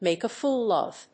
アクセントmàke a fóol of…